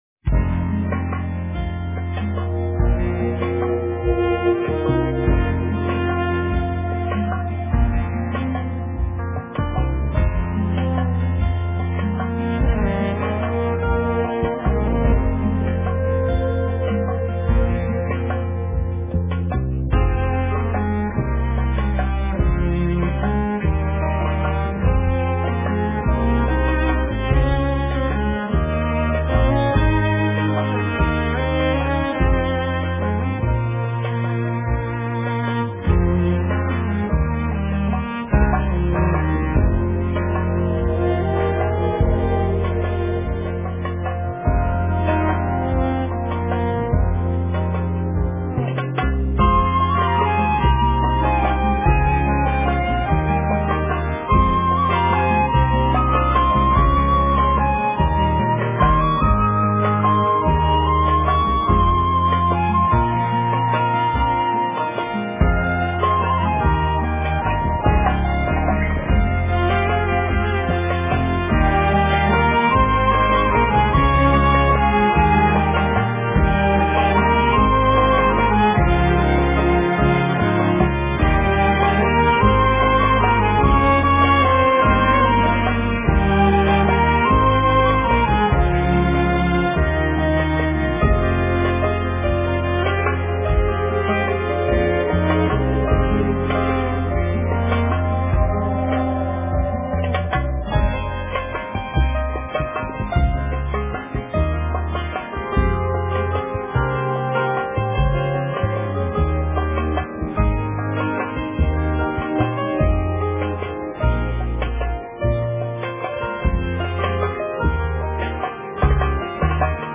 數十部好萊塢電影配樂魔笛大師，讓你觸摸到那傳說中的心靈秘境
類別： 新世紀音樂 / 民族融合
探索克爾特音樂最核心